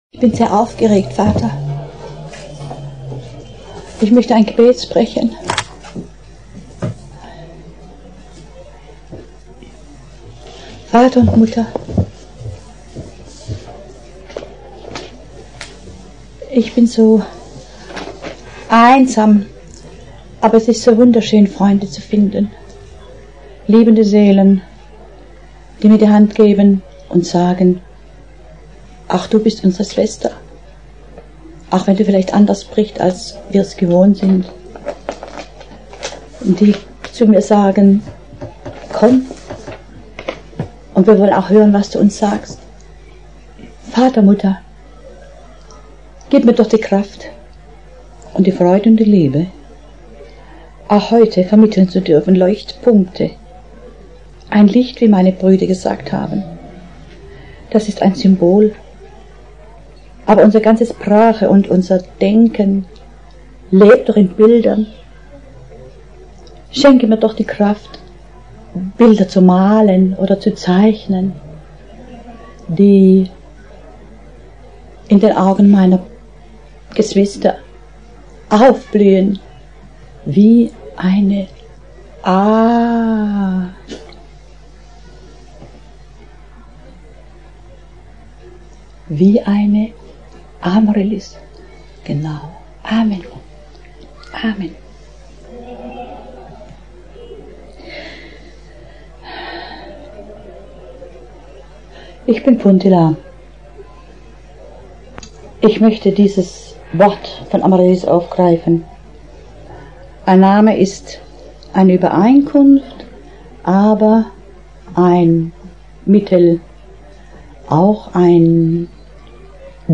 107. Vortrag
107. Bandaufnahme am 26. August 1990 in Biberach